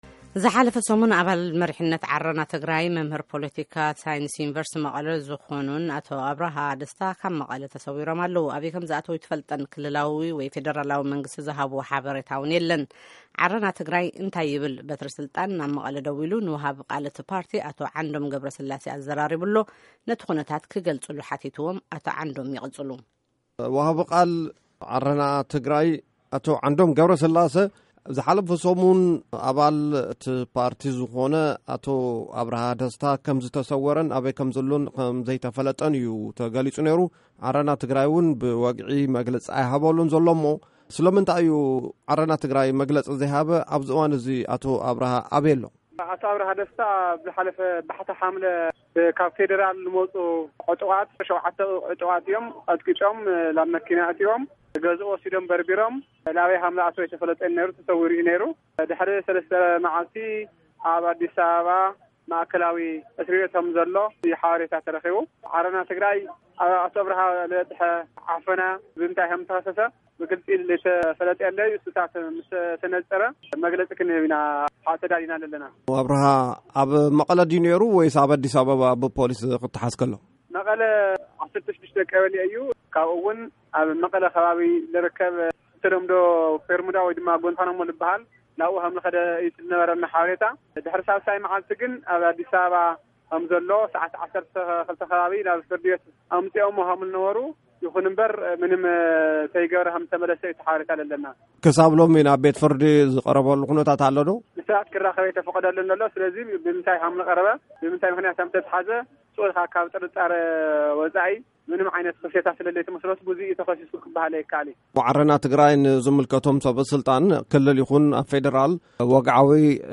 ንሙሉእ ቃለ-ምልልስ ኣዳምጹ